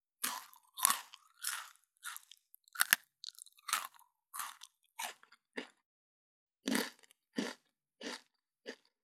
18.スナック菓子・咀嚼音【無料効果音】
ASMR